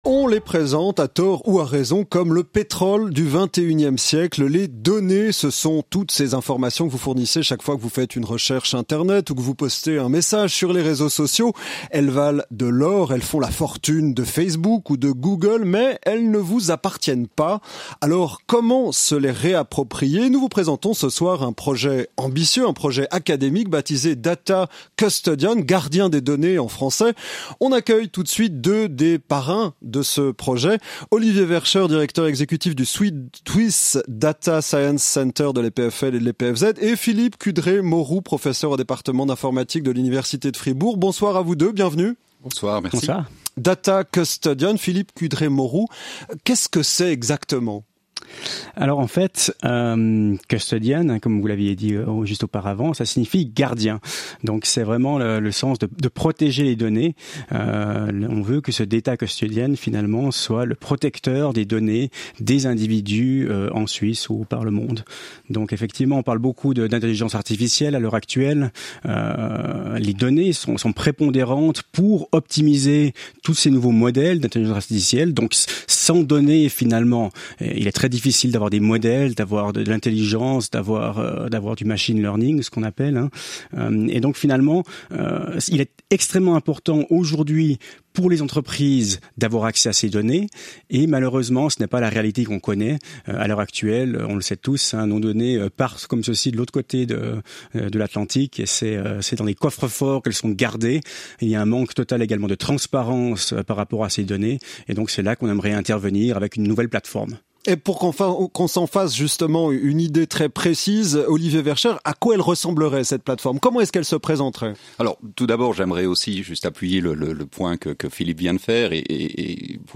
FORUM – Interviews